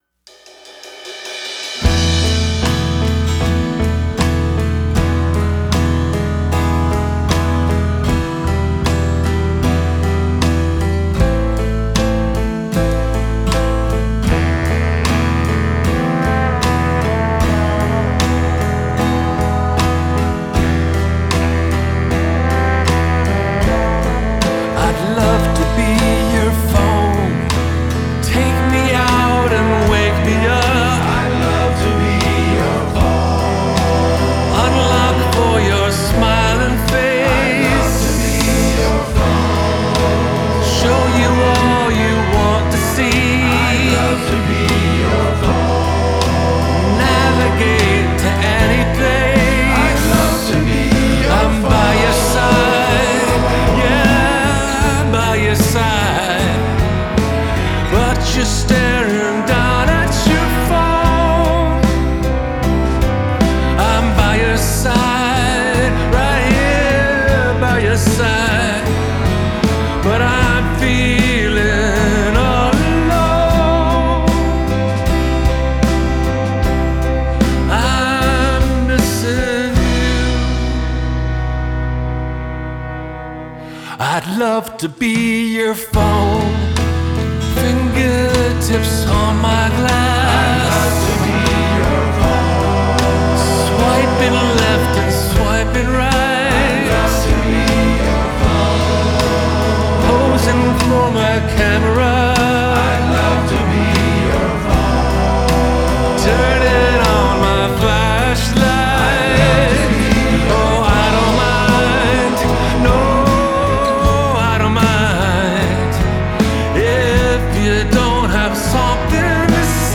vocals, acoustic guitars, piano
drums, percussion
trumpet
trombone
tenor saxophone
baritone saxophone
backing vocals